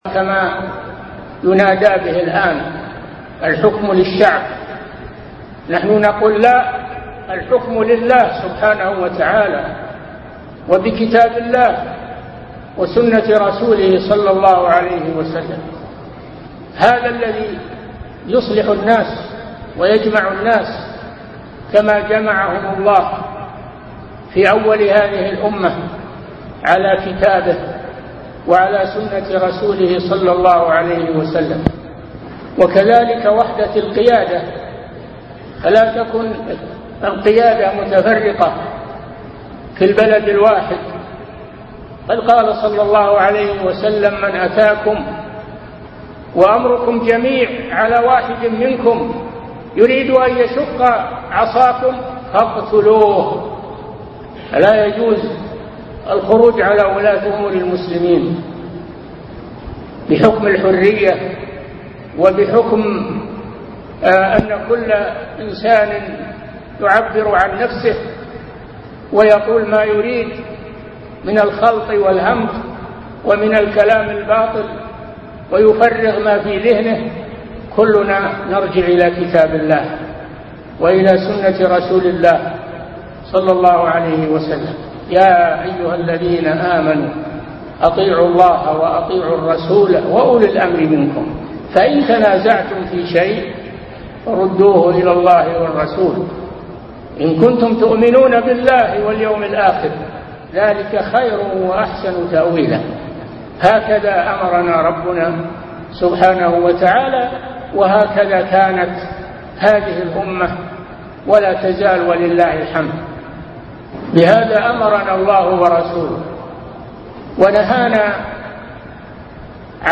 Format: MP3 Stereo 22kHz 64Kbps (CBR)